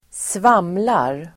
Ladda ner uttalet
svamla verb, ramble, talk drivelGrammatikkommentar: A &Uttal: [²sv'am:lar] Böjningar: svamlade, svamlat, svamla, svamlarSynonymer: dilla, jamsa, tramsa, yraDefinition: prata osammanhängande